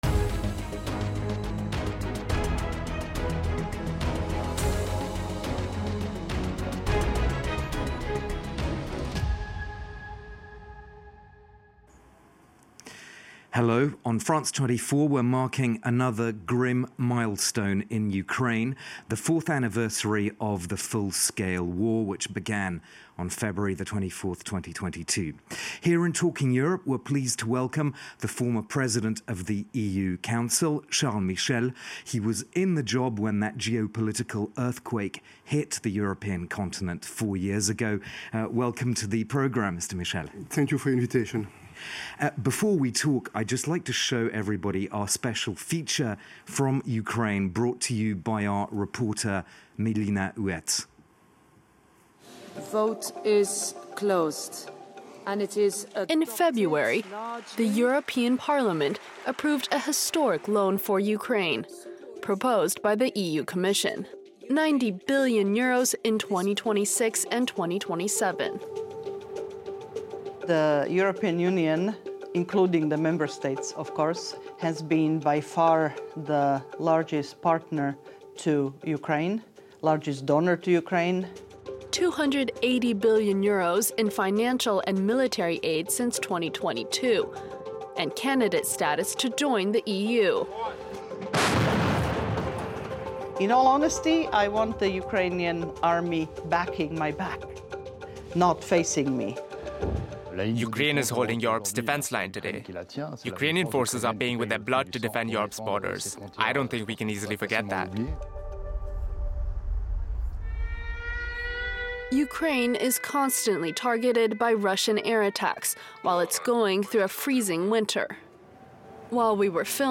As FRANCE 24 covers another grim milestone in Ukraine – four years of full-scale war – Talking Europe hosts the former president of the EU Council, Charles Michel, who was in the job when that geopolitical earthquake struck the European continent on February 24, 2022.